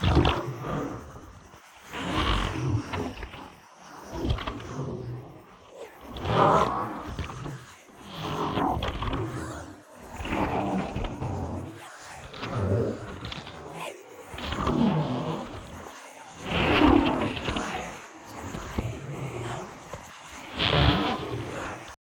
ghost_idle.ogg.bak